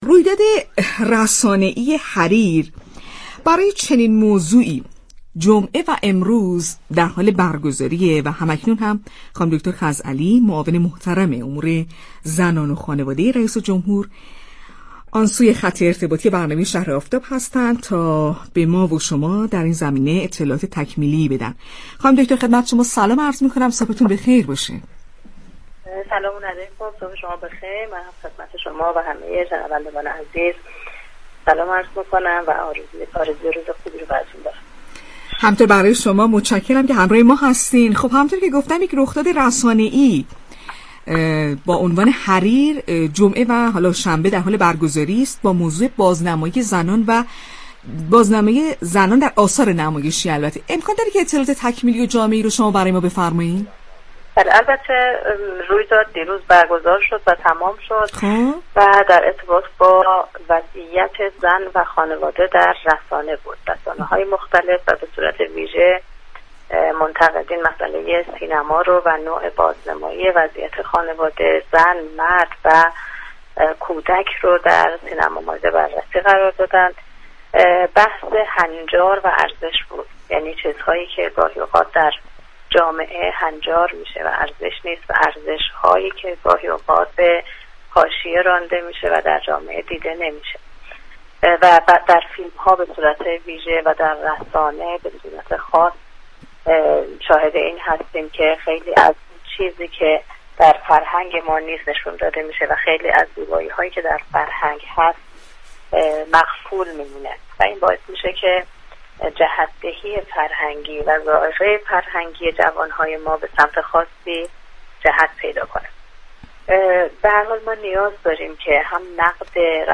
انسیه خزعلی معاون امور زنان و خانواده ریاست جمهوری در گفت و گو با «شهر آفتاب»